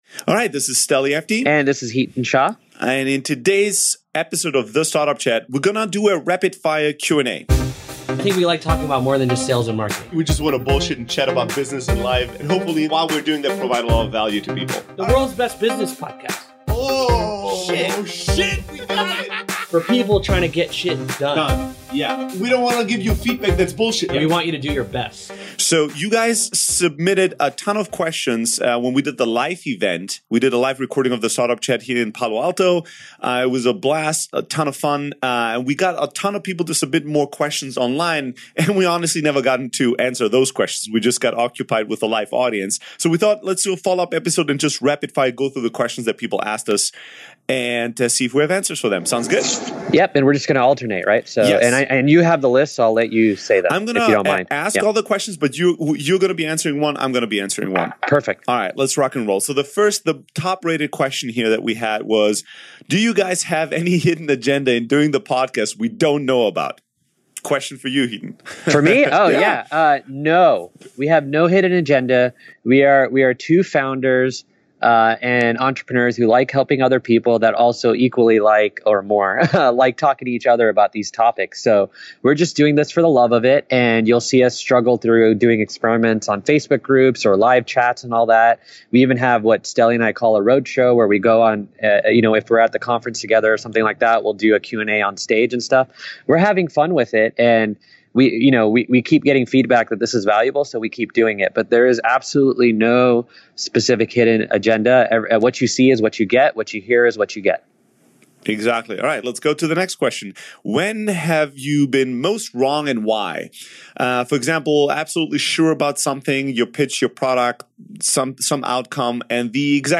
This episode is a Q & A session.